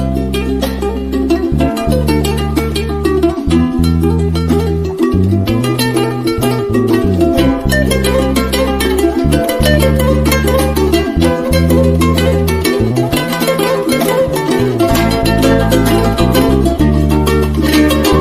Category Instrumental